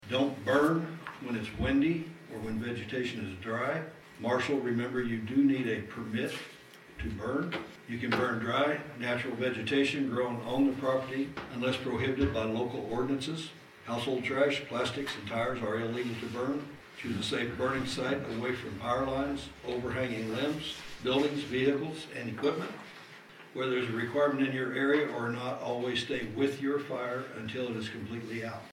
The Marshall City Council discussed spring cleanup tips for residents and announced a start date for citywide yard waste pick up during its meeting on Monday, March 18.
Ward 1 Councilman Dewey Hendrix details provides tips for the burning of materials in the city.